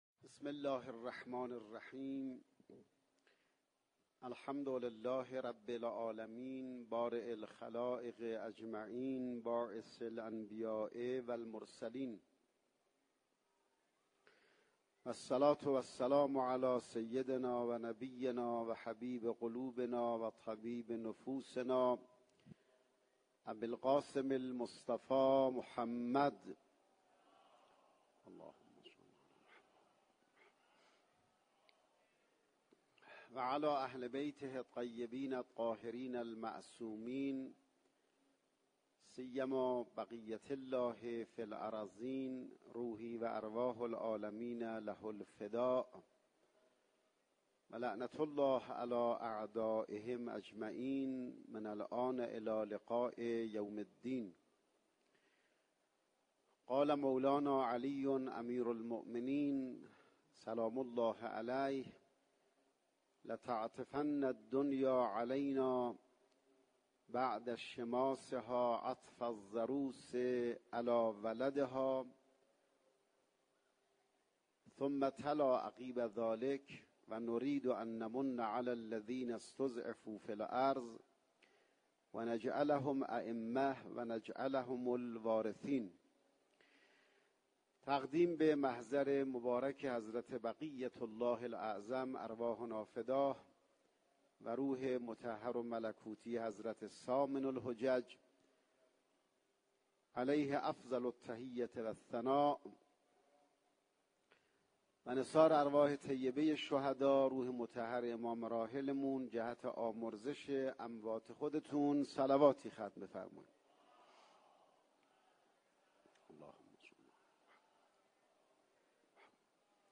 دانلود سخنرانی امام زمان(ع) از دیدگاه نهج البلاغه